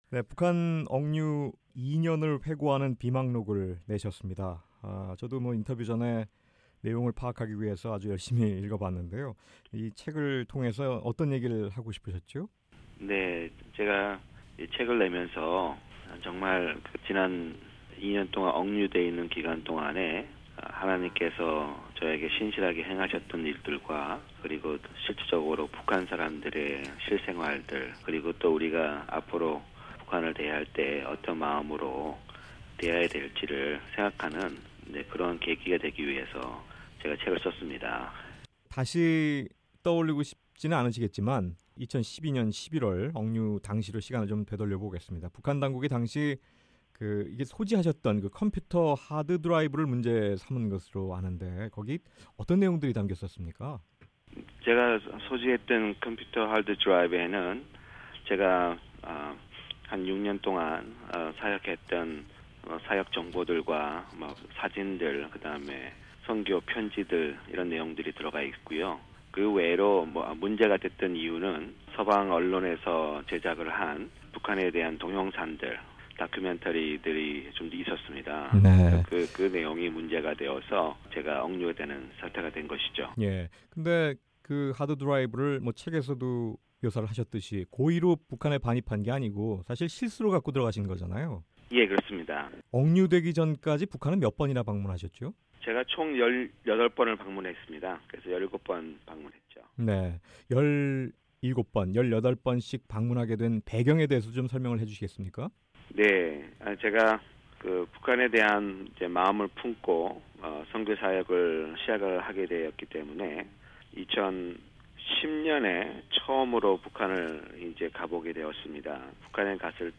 [인터뷰 오디오] '북한 억류' 비망록 낸 케네스 배